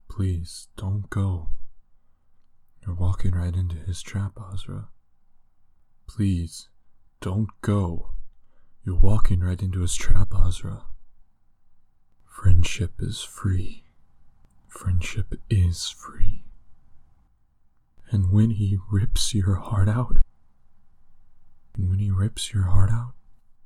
Voice: Shy, rough, deep, gruff, slightly airy, distant